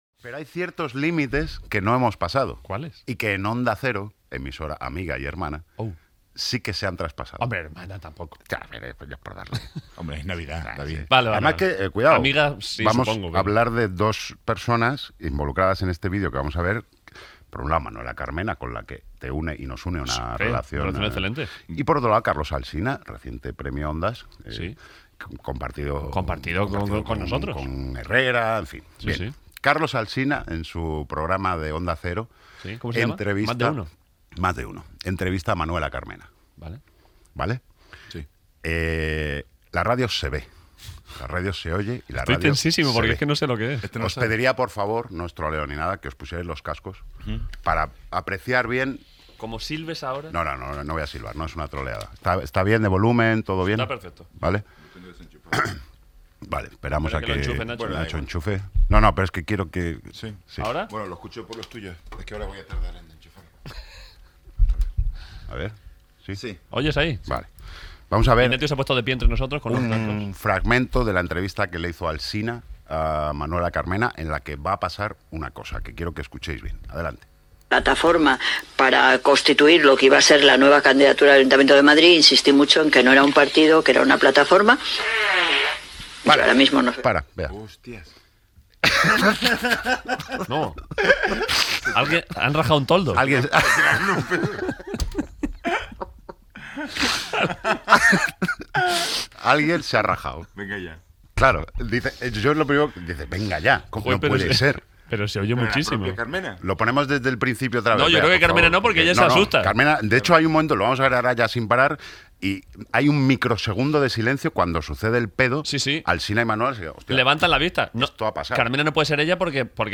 Espai dedicat a comentar l'entrevista feta a l'exalcaldessa de Madrid, Manuela Carmena, al programa "Mas de uno" d'Onda Cero, en el qual s'escolta un pet
Entreteniment